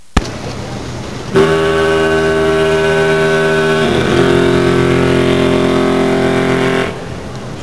Nantucket Lightship in South Portland, ME
foghorn from
Foghorn wav